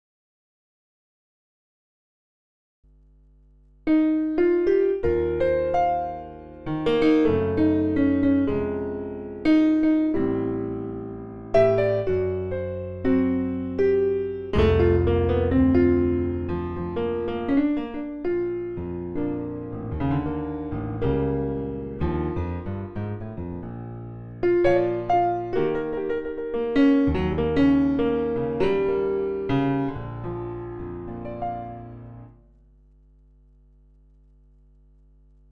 44.1 35s stereo
Recording is with some hum because of my tv cable having been not detached, no sequencing, editing, extra effect or software used at all.